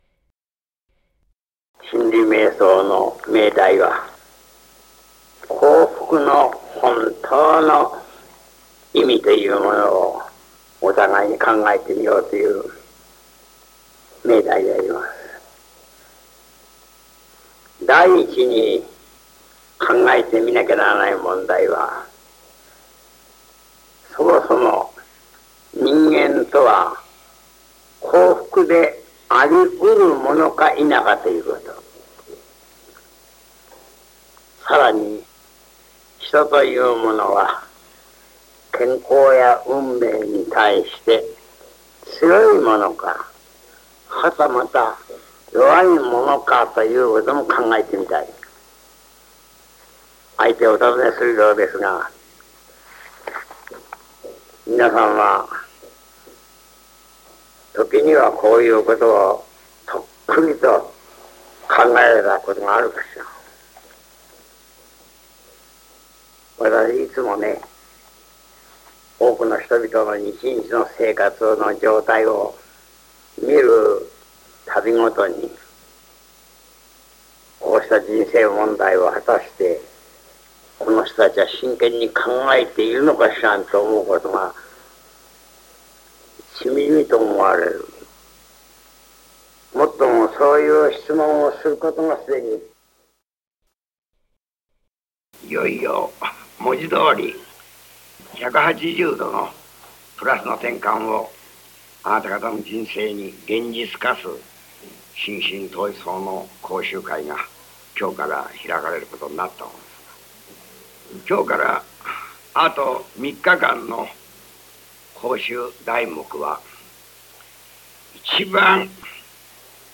中村天風 本人の肉声で聴き学ぶ 講演録CD版・デジタル版
・文字起こしテキストＰＤＦ付き ※記録音源の劣化のため、編集をしておりますが音質が良くない巻やノイズがございます。